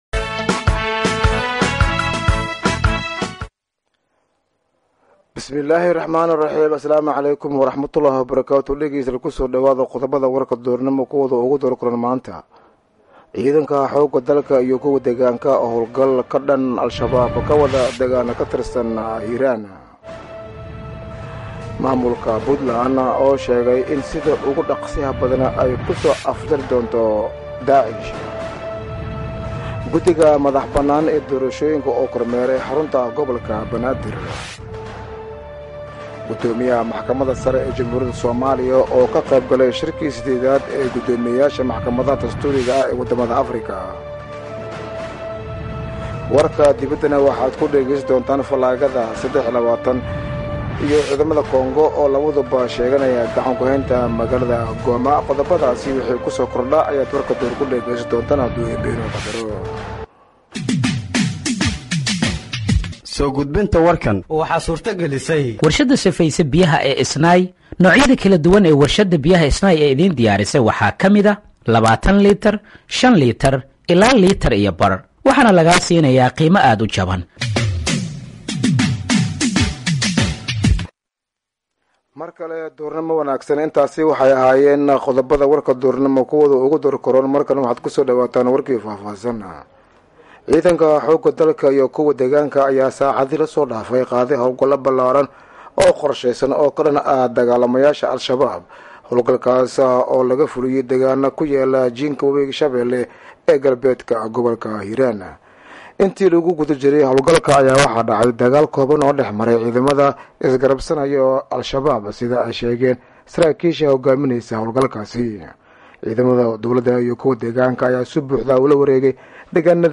Dhageeyso Warka Duhurnimo ee Radiojowhar